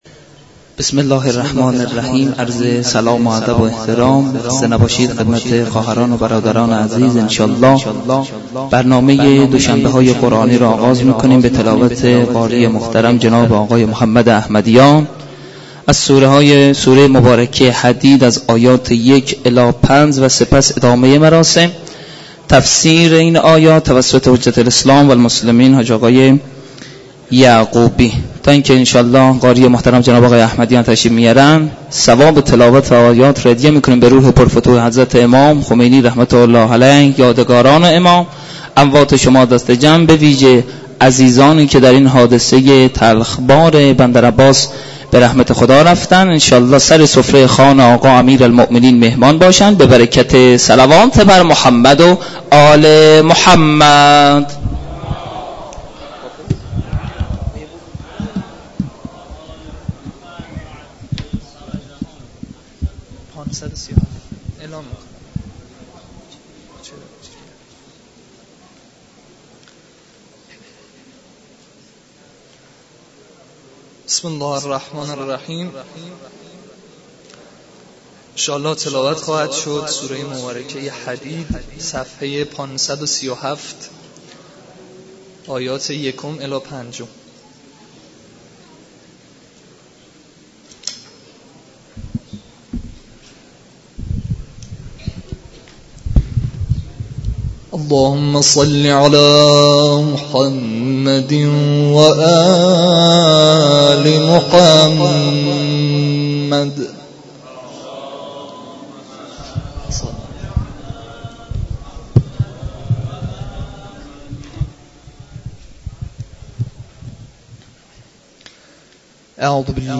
برگزاری مراسم معنوی دوشنبه های قرآنی در مسجد دانشگاه کاشان